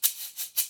Free MP3 shakers sounds 2